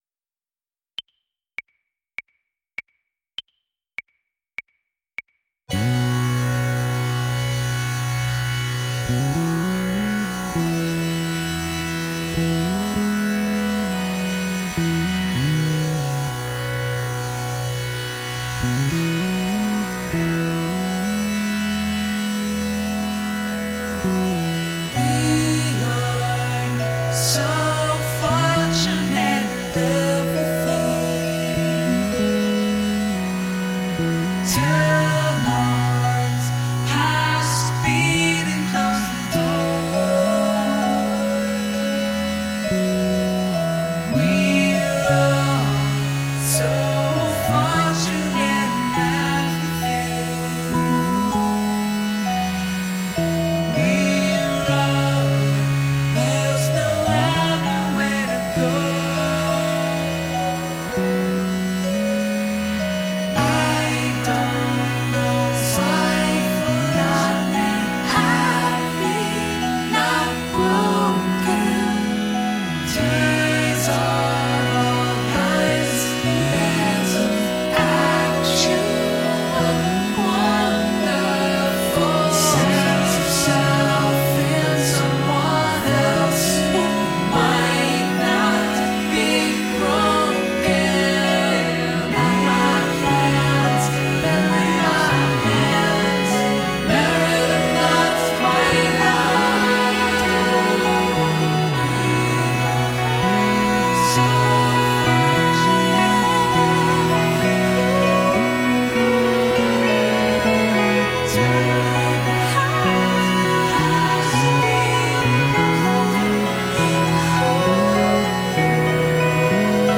Defiantly homemade pop rock music.